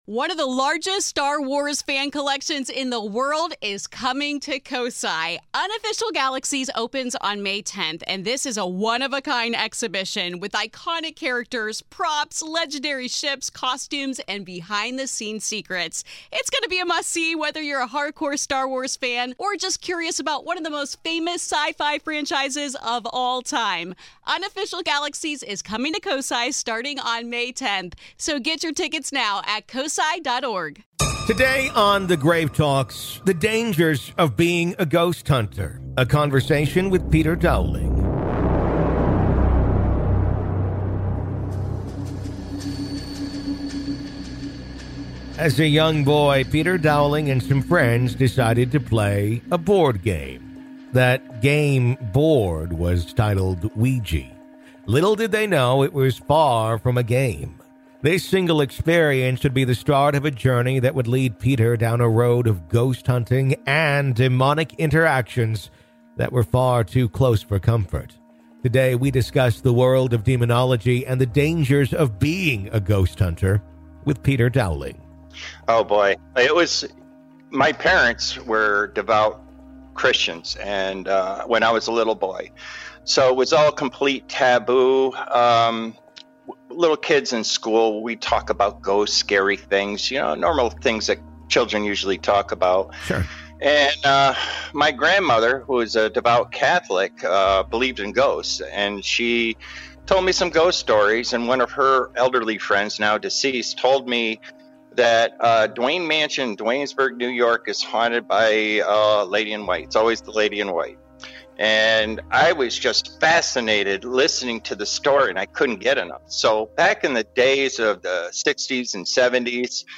In part two of our interview